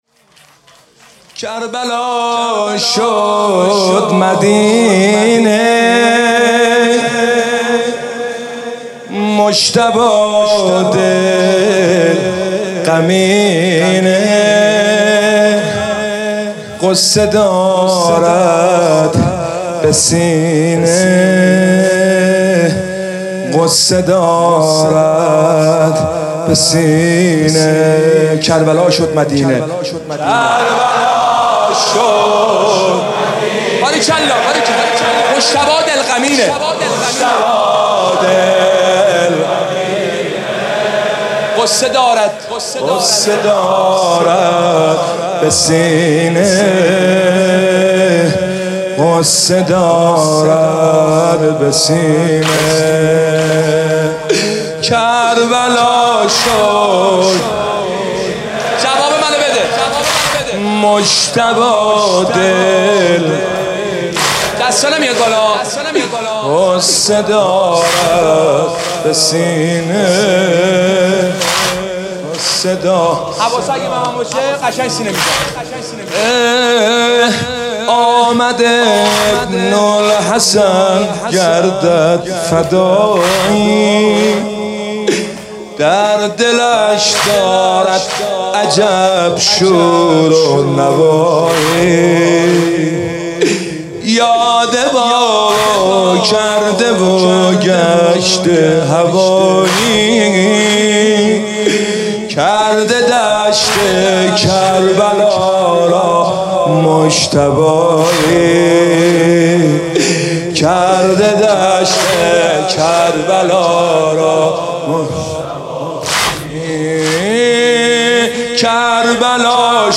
هیئت روضه العباس (ع)